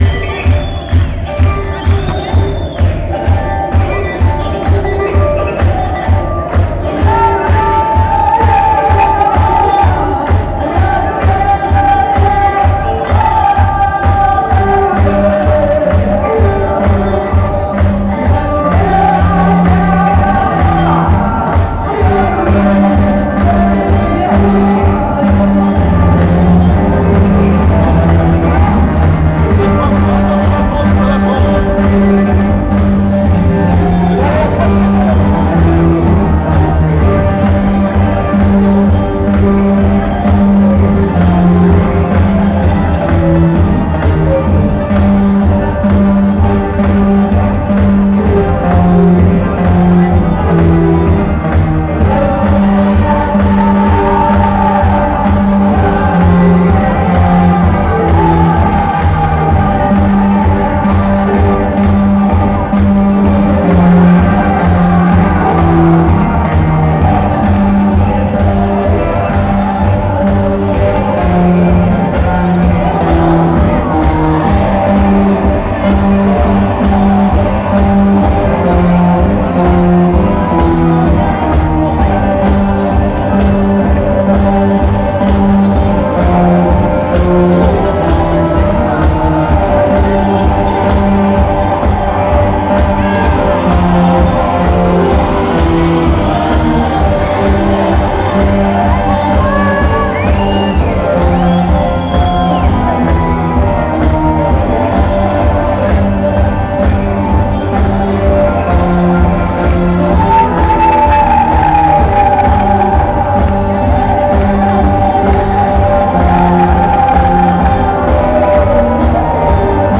la grande Halle de la Villette
et s’aventure vers un rock dansant, psychédélique et humain.
(Live)
enregistrements qui saturent